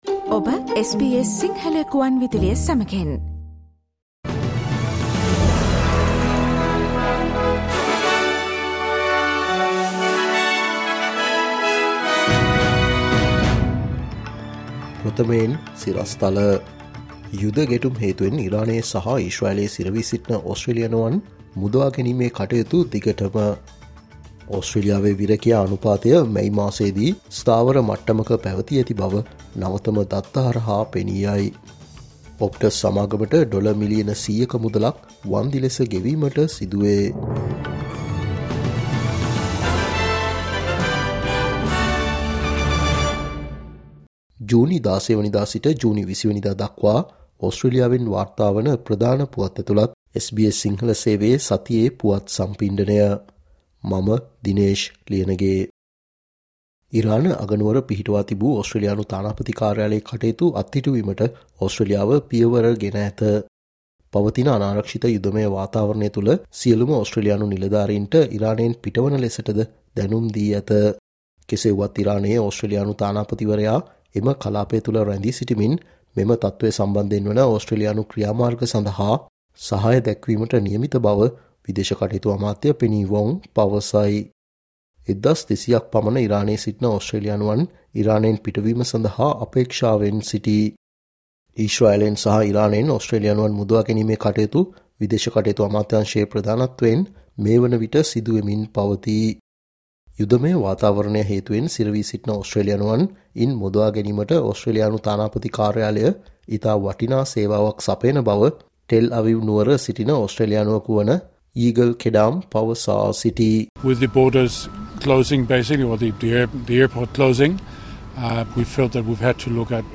ජුනි 16 වනදා සිට ජුනි 20 වනදා දක්වා වන මේ සතියේ ඕස්ට්‍රේලියාවෙන් වාර්තාවන සුවිශේෂී පුවත් ඇතුලත් සතියේ පුවත් ප්‍රකාශයට සවන් දෙන්න